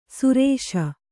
♪ surēśa